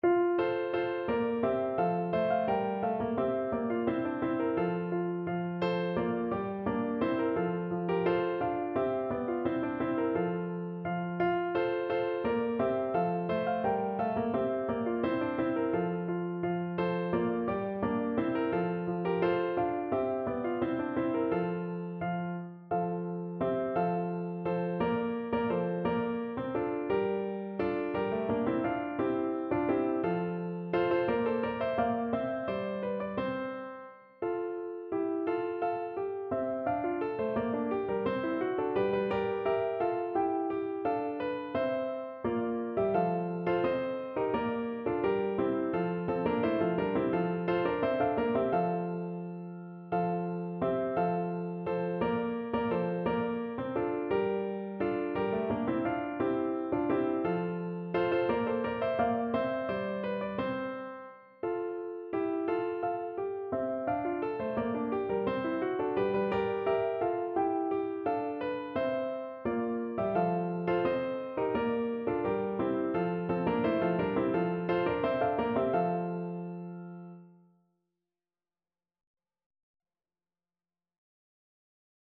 Classical Weelkes, Thomas Since Robin Hood Piano version
No parts available for this pieces as it is for solo piano.
F major (Sounding Pitch) (View more F major Music for Piano )
= 172 Fast and energetic
4/4 (View more 4/4 Music)
Piano  (View more Intermediate Piano Music)
Classical (View more Classical Piano Music)